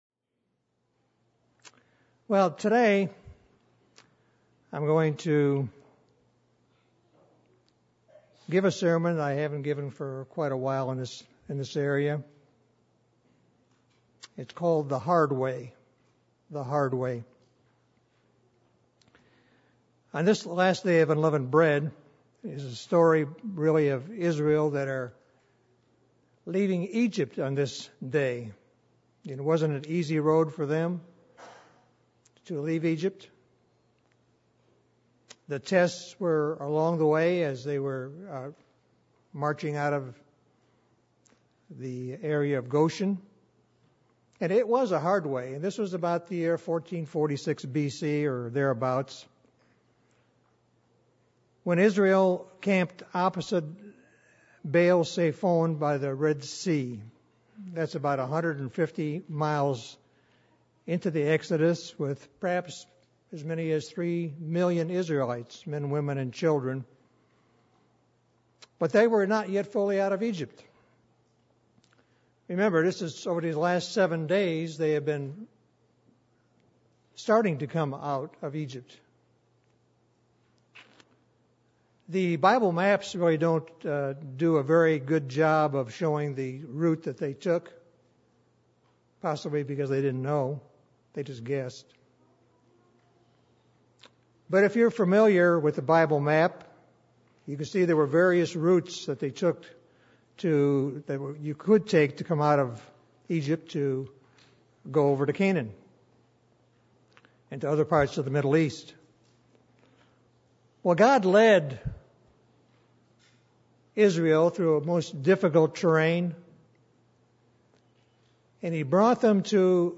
This sermon was given for the Last Day of Unleavened Bread.